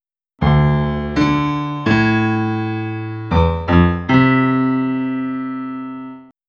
#23 Piano : clean Real-Time Iterative Spectrogram Inversion (RTISI) (University of Music and Performing Arts Graz - o:133464)